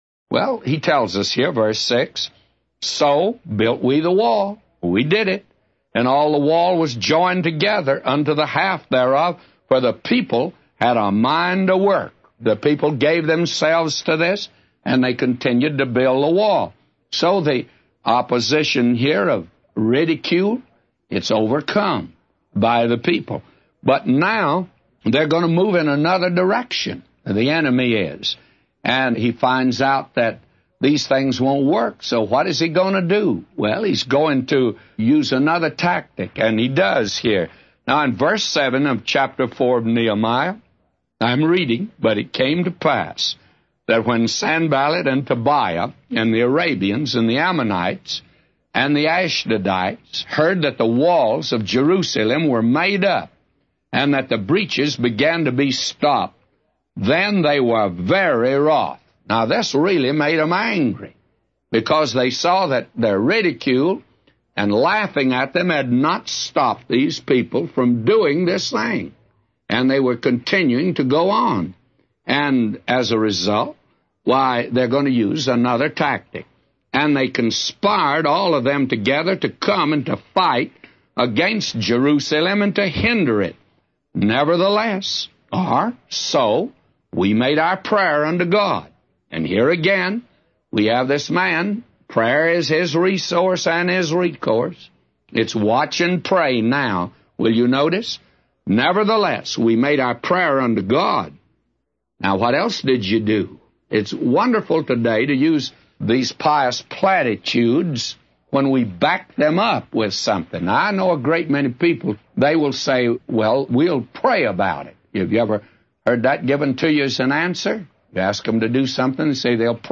A Commentary By J Vernon MCgee For Nehemiah 4:6-999